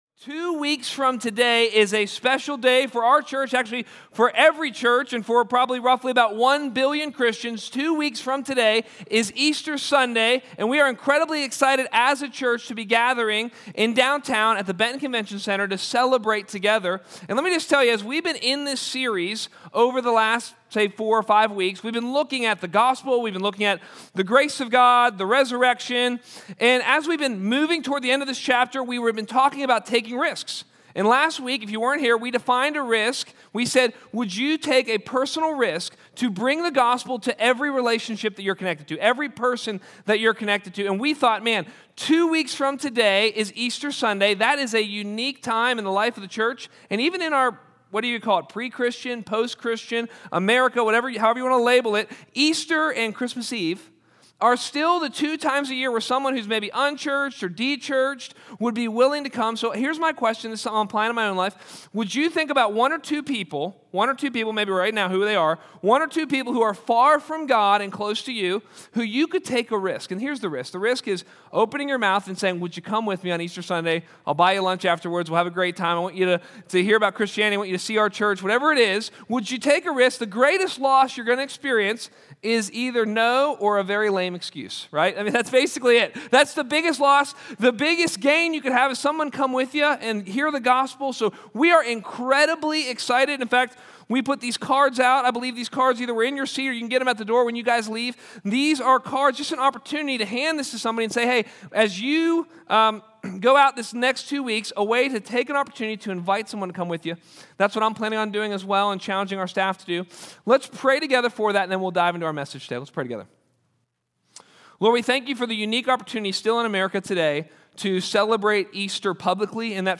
*Note: We apologize for the quality of the audio. It was pulled from a source that glitched out during recording.